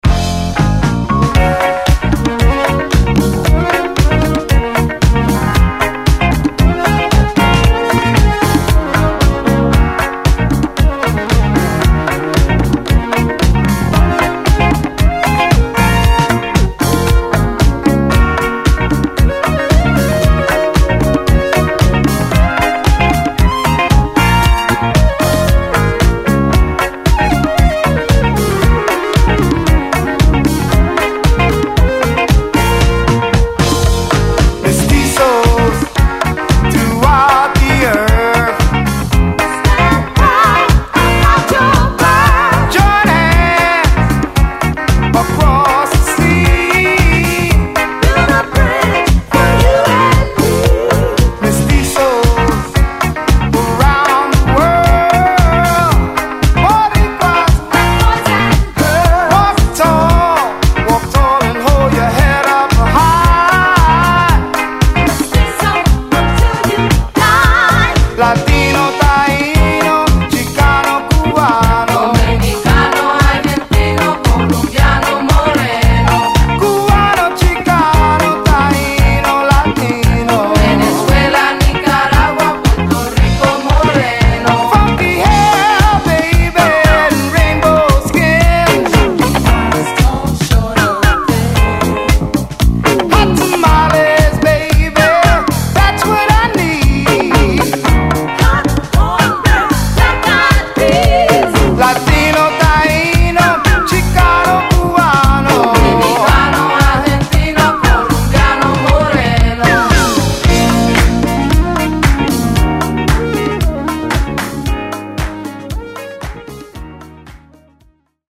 ・ DISCO 70's 12'